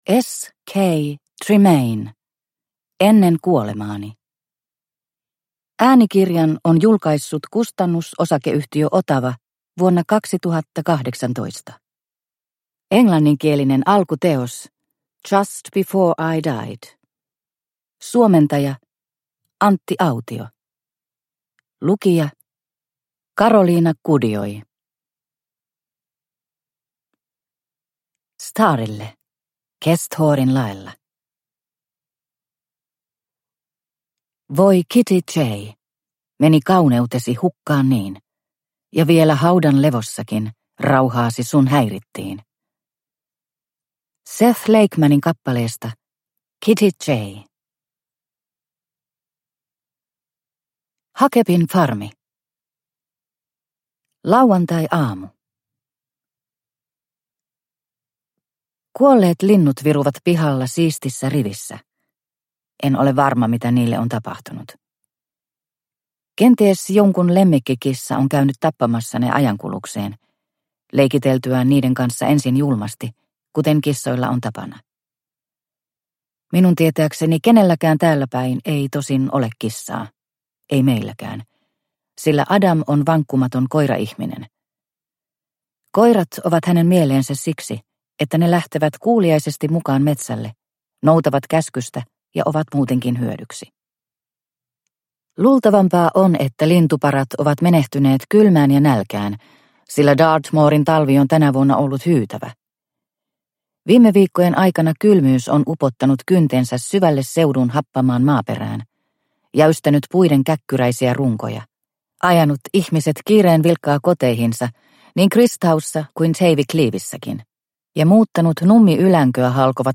Ennen kuolemaani – Ljudbok – Laddas ner